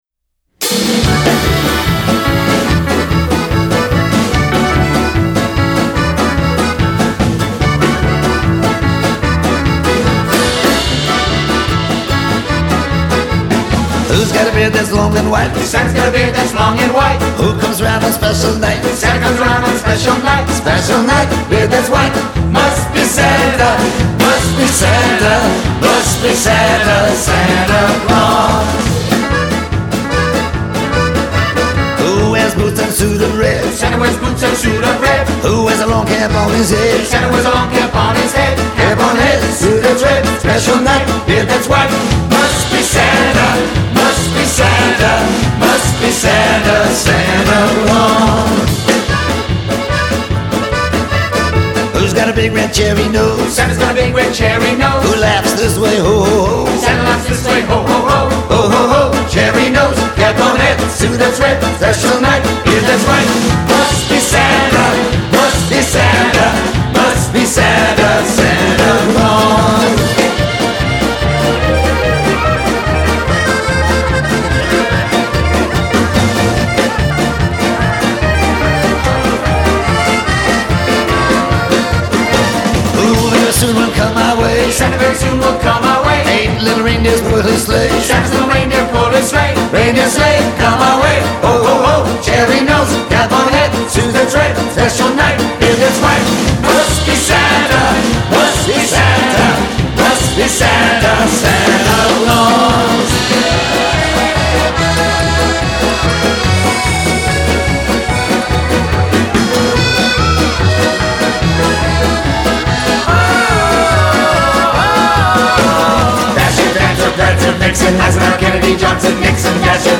He croaks and whines with gusto and
rollicking accordion-laced